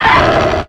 Cri de Lucario dans Pokémon X et Y.